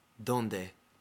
Ääntäminen
UK : IPA : /ˈɹaʊnd/ US : IPA : /ˈɹaʊnd/